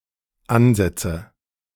, meaning: "initial placement of a tool at a work piece", plural ansatzes[1] or, from German, ansätze /ˈænsɛtsə/; German: [ˈʔanzɛtsə]
De-Ansätze.ogg.mp3